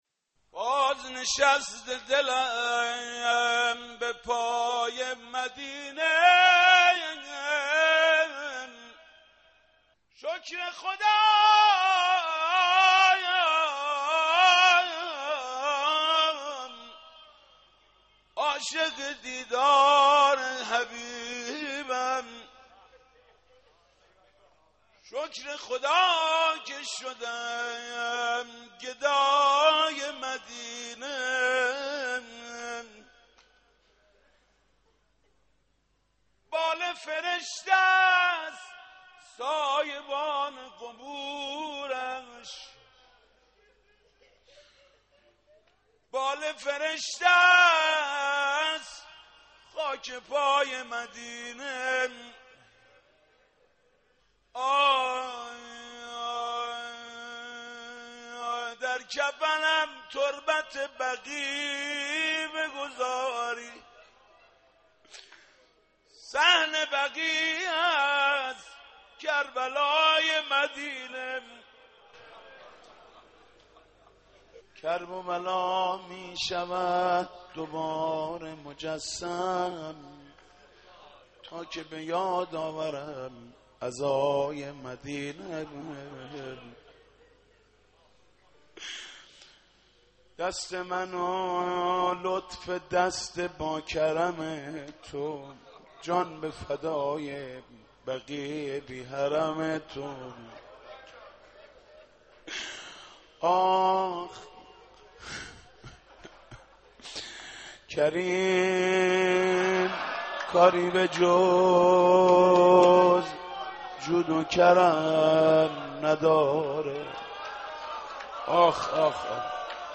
مرثیه خوانی پیرامون هشتم شوال سالروز تخریب قبور ائمه بقیع(علیهم السلام)